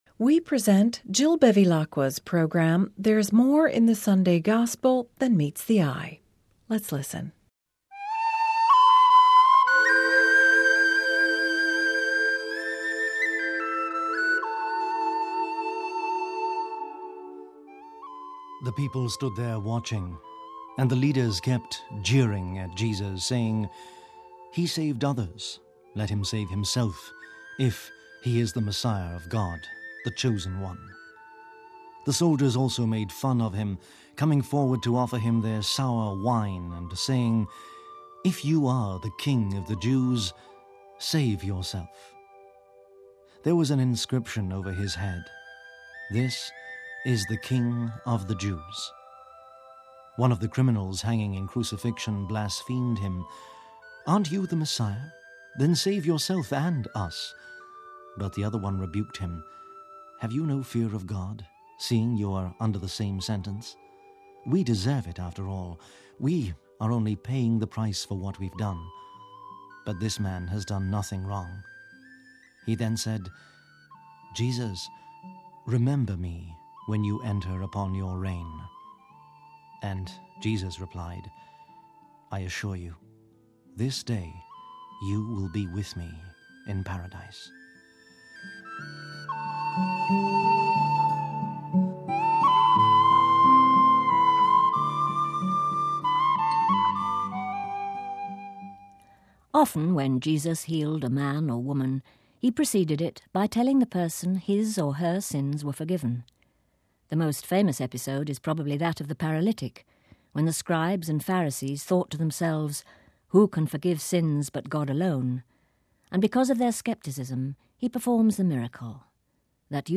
readings and reflections for the Thirtyfourth Sunday of the Year, Feast of Christ the King